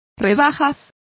Complete with pronunciation of the translation of sales.